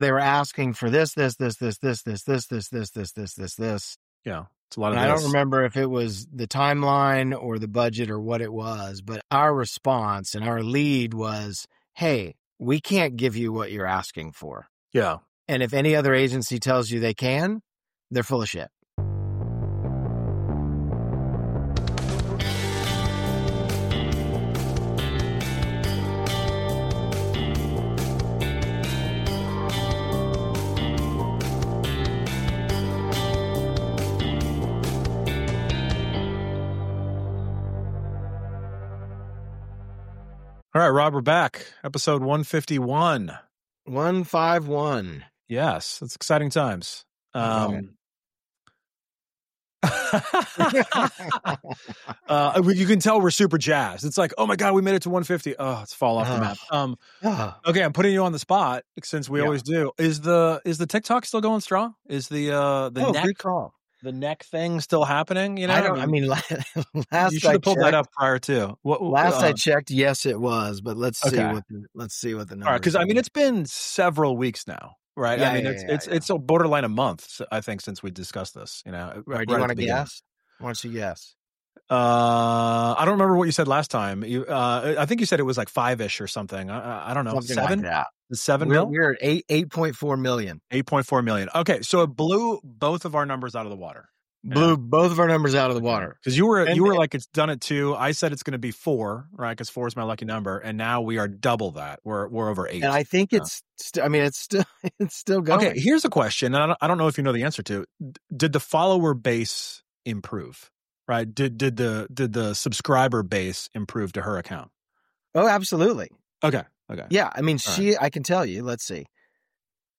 the podcast is a casual discussion around the craft of creative marketing as it relates to business challenges.